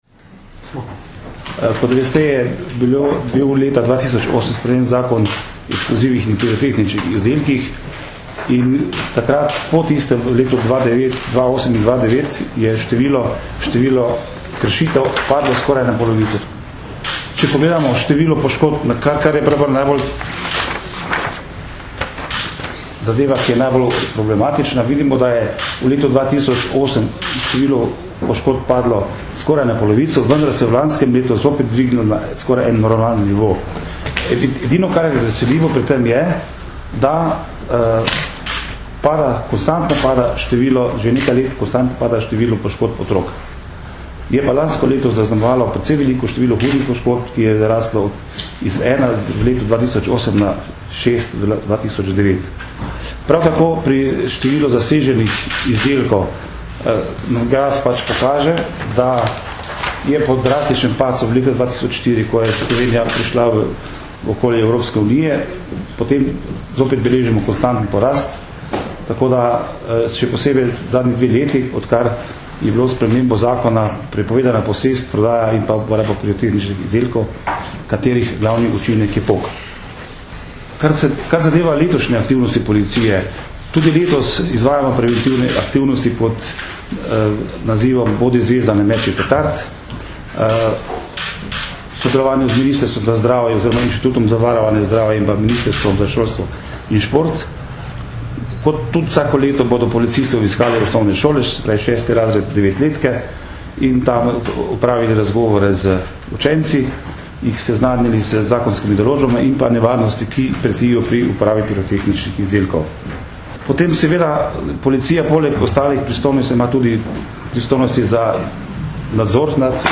Na današnji novinarski konferenci smo zato predstavili naše aktivnosti in prizadevanja, da bi bilo med božično-novoletnimi prazniki čim manj kršitev in telesnih poškodb zaradi neprevidne, nepremišljene in objestne uporabe pirotehničnih izdelkov.